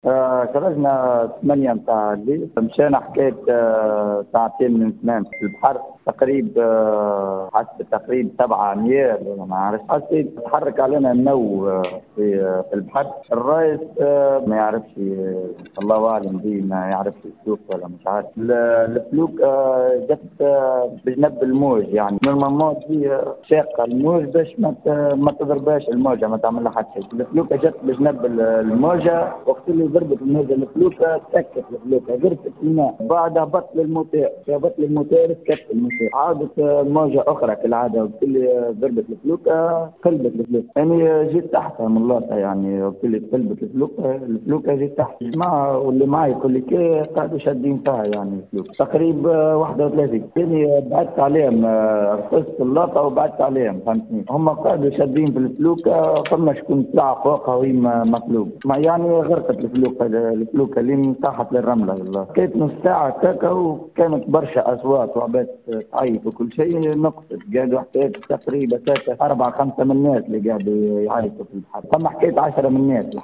أحد الناجين من غرق المركب في سواحل المنستير يروي تفاصيل الحادثة (تسجيل) - Radio MFM